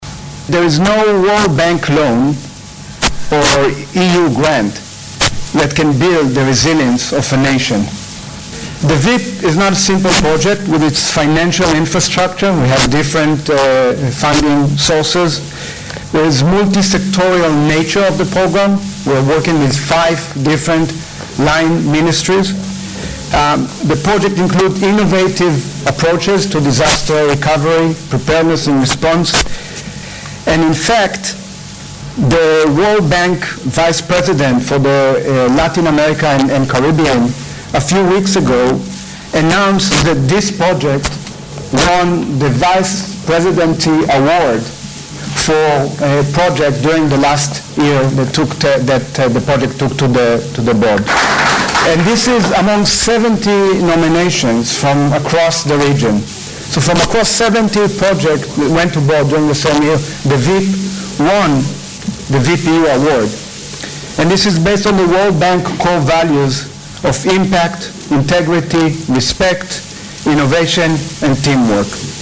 as he addressed the launch of the VEEP Project on Thursday.